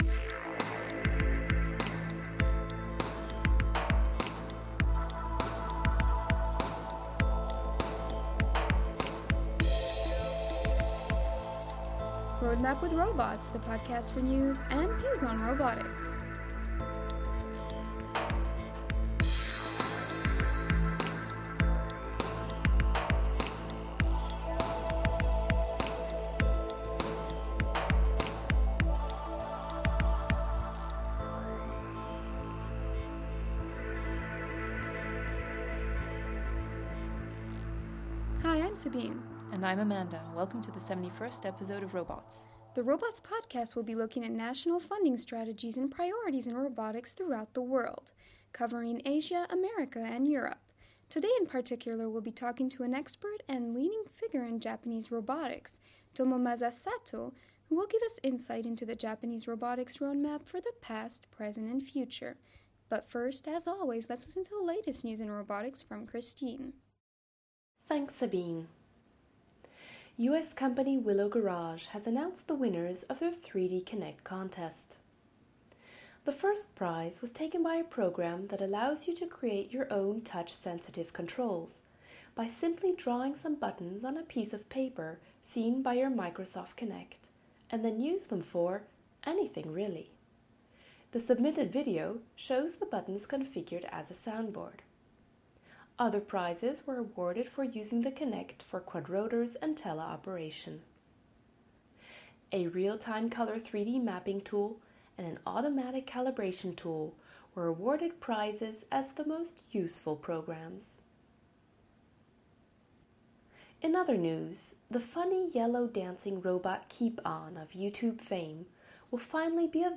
Links: Download mp3 (11.3 MB) Subscribe to Robots using iTunes Subscribe to Robots using RSS METI tags: podcast Podcast team The ROBOTS Podcast brings you the latest news and views in robotics through its bi-weekly interviews with leaders in the field.